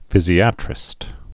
(fĭzē-ătrĭst, fĭ-zīə-trĭst)